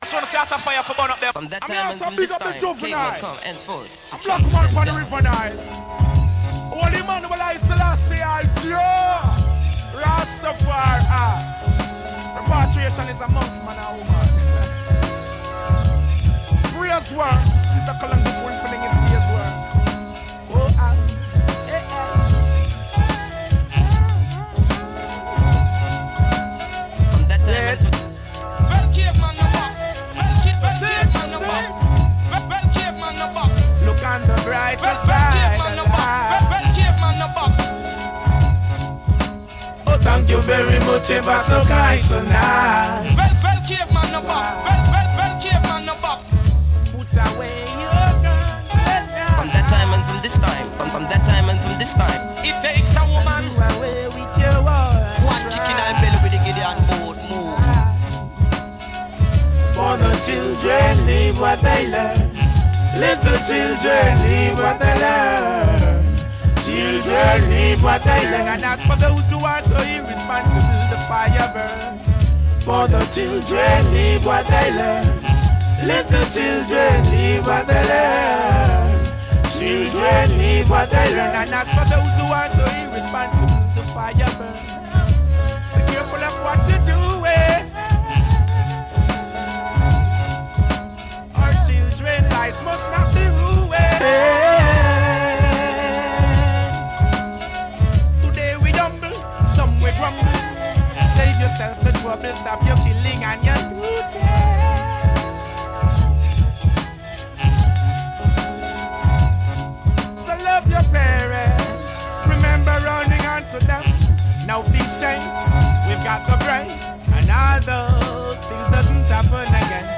Reggae Cultural Sound System